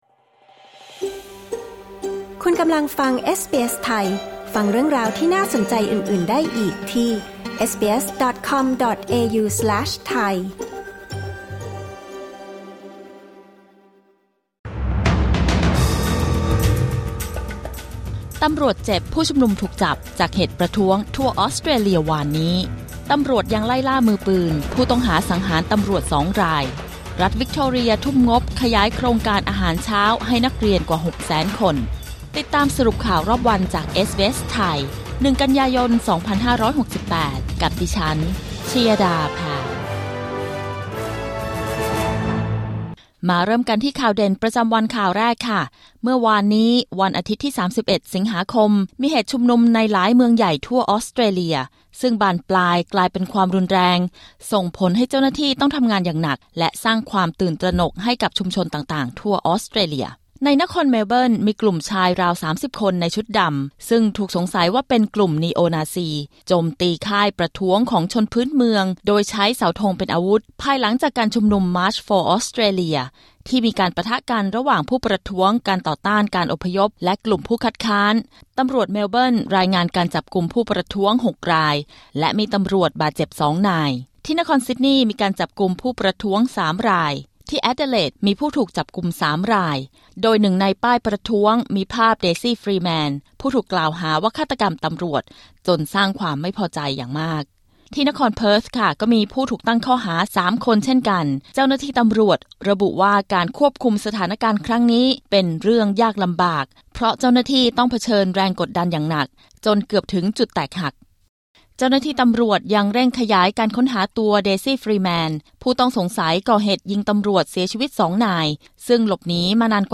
สรุปข่าวรอบวัน 1 กันยายน 2568